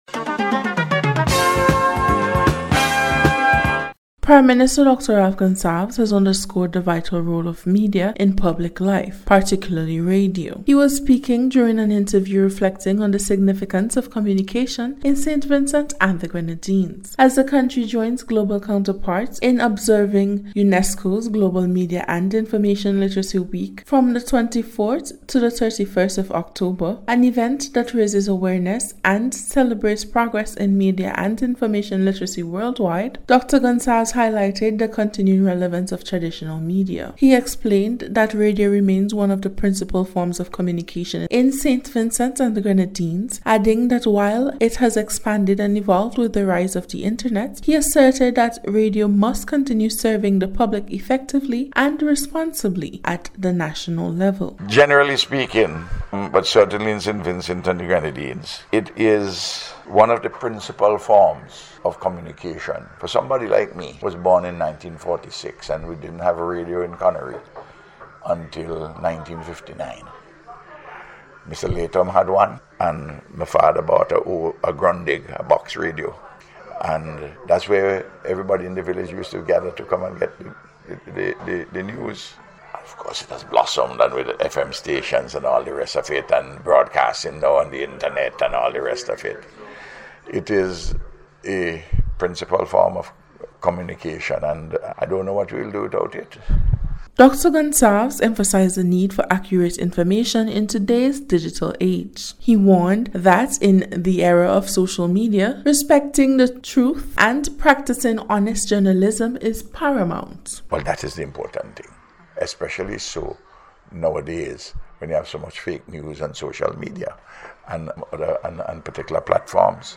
In today’s special report, Prime Minister Dr. Ralph Gonsalves highlights the vital role of media, especially radio, in informing and shaping public life in Saint Vincent and the Grenadines.